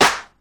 Royality free steel snare drum sound tuned to the F note. Loudest frequency: 1480Hz
• '00s Natural Hip-Hop Acoustic Snare Sample F Key 05.wav
00s-natural-hip-hop-acoustic-snare-sample-f-key-05-U5z.wav